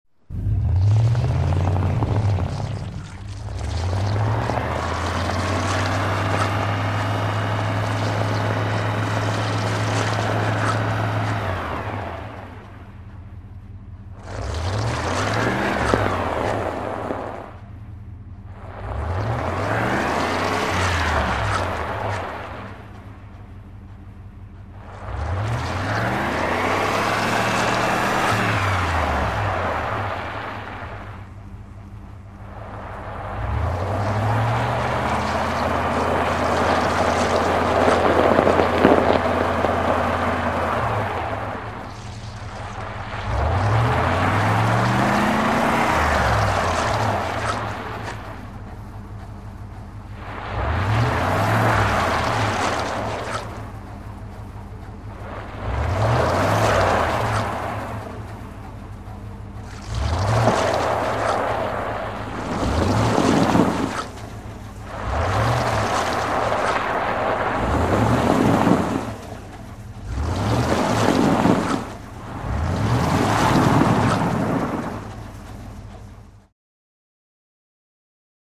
Car Close Maneuvers, Tire Stuck In Gravel And Mud, Spins With Car's Acceleration, Close Point of View.